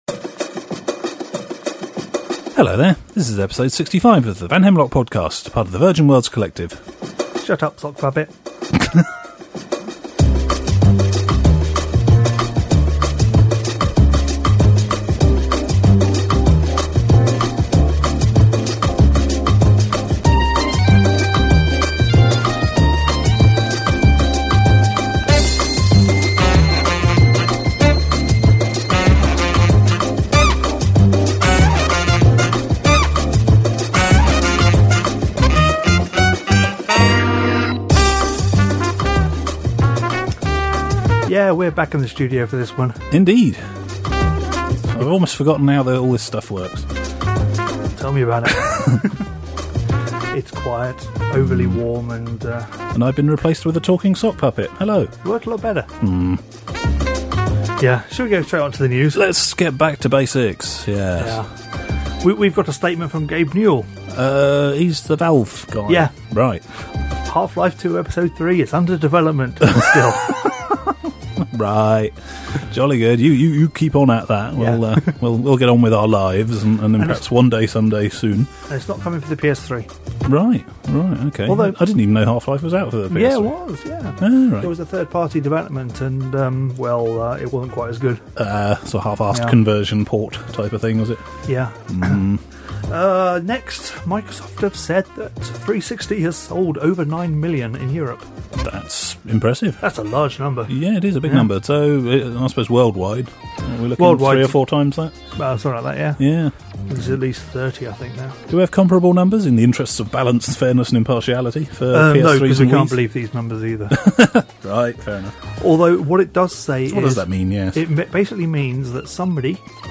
This week we return to an overly hot studio and what we laughably call our normal format to give you some news and a long overdue update on what we’ve been playing.